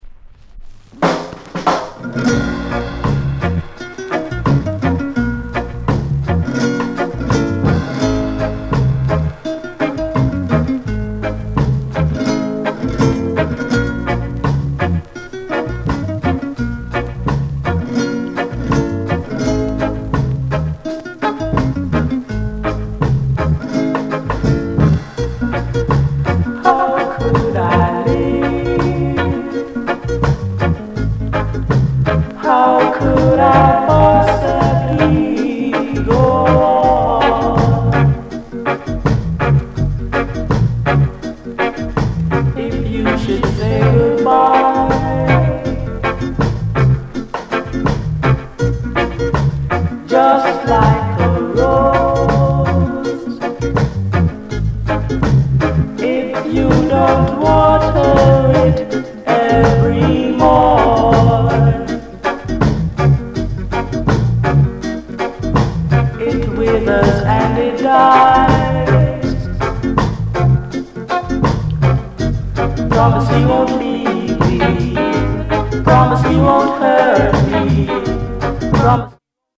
NICE ROCKSTEADY